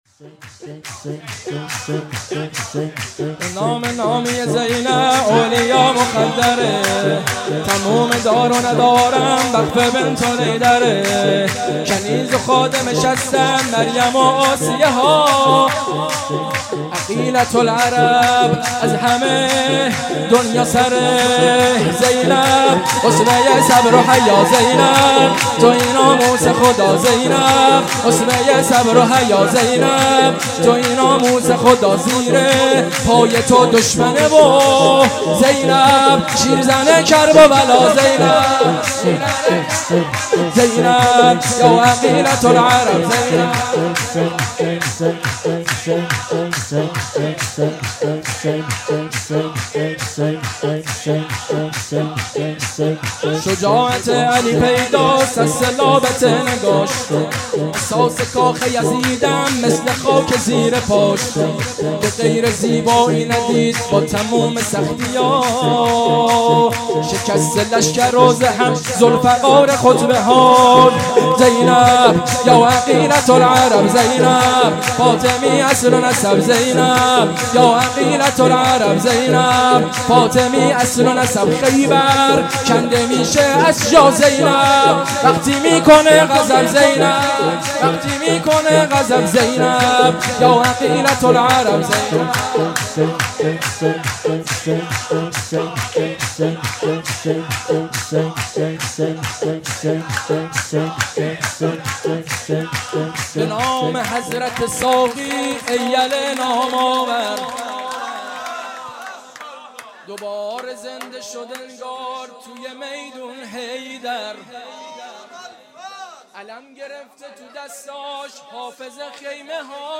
مجموعه صوتی مراسم ولادت حضرت زینب سلام الله علیها 97
سرود دوم / پیبشنهاد دانلود